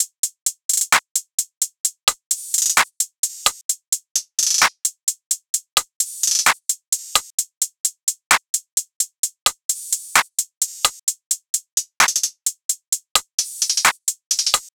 SOUTHSIDE_beat_loop_trill_top_01_130.wav